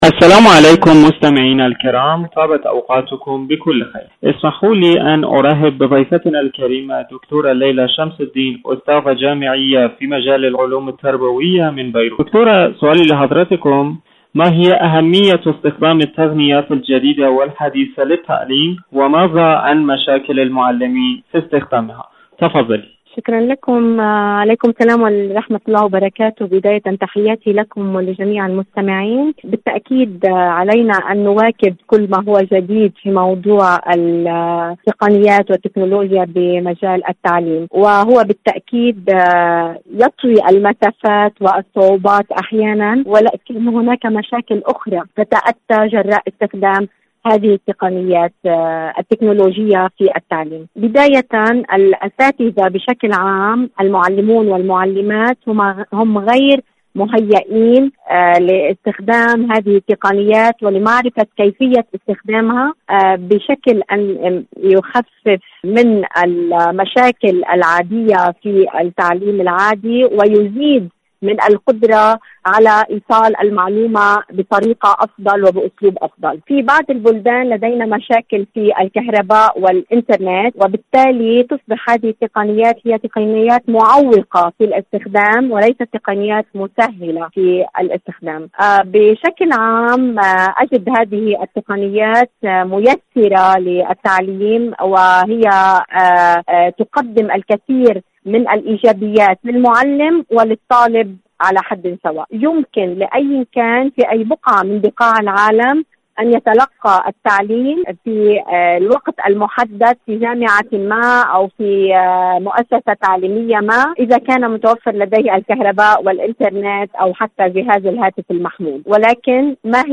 إذاعة طهران-معكم على الهواء: مقابلة إذاعية